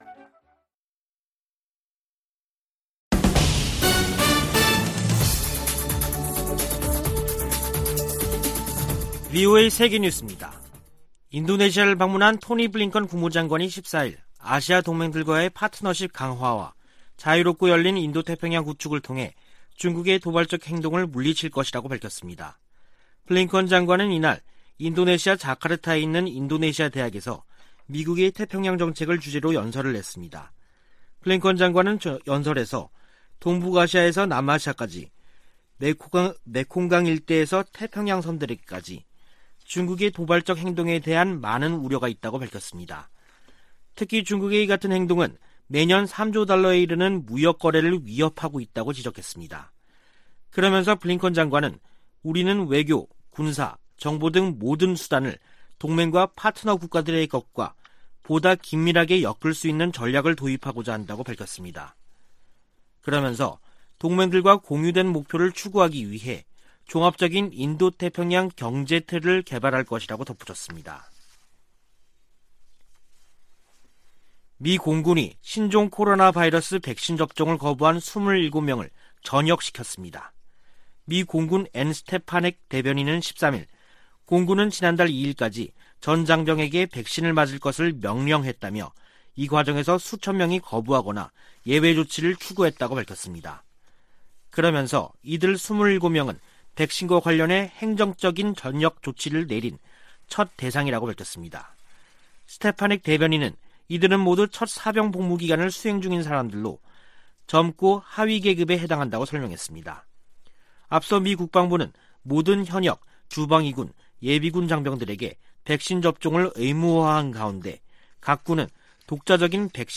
VOA 한국어 간판 뉴스 프로그램 '뉴스 투데이', 2021년 12월 14일 3부 방송입니다. 미 국무부는 베이징 동계올림픽 외교적 보이콧에 관해 ‘한국 스스로 결정할 일’이라고 밝혔습니다. 미 국방부는 한국군 전시작전통제권 전환을 위한 완전운용능력(FOC) 평가를 내년 여름에 실시하는 계획을 재확인했습니다. 미 재무부가 북한 내 인권 유린에 연루된 개인과 기관을 제재한 효과가 제한적일 것이라고 전문가들은 평가했습니다.